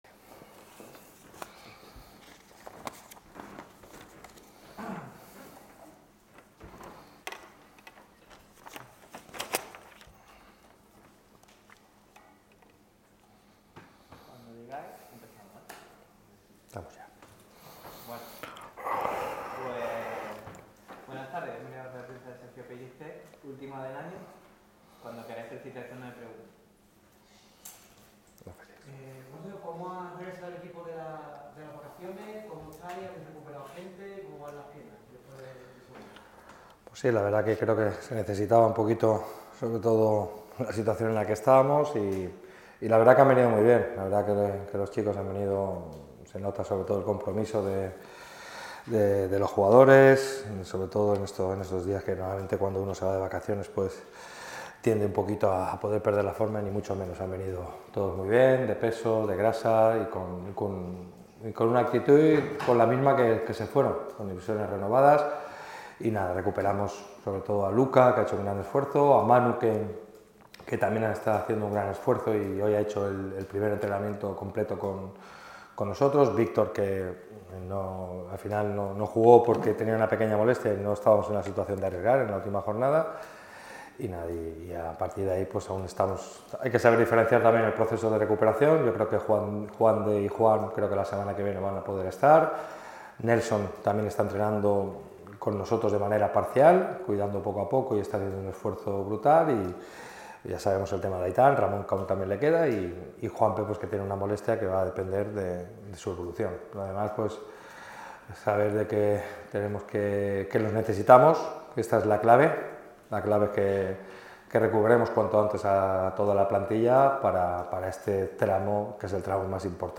El técnico de Nules ha comparecido ante los medios en la sala de prensa de La Rosaleda con motivo de la previa del CF Intercity – Málaga CF. El técnico ha hecho un balance de las vacaciones y repasa el estado actual del equipo tras el parón.